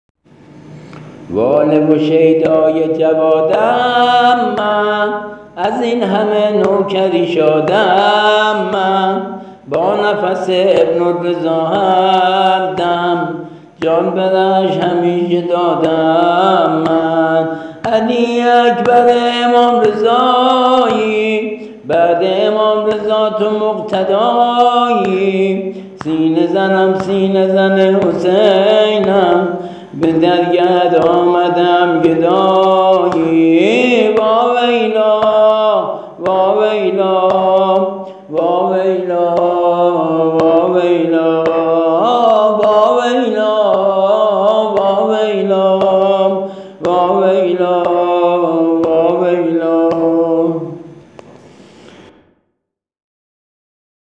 ◾نوحه سینه زنی
◾سبک و ملودی جدید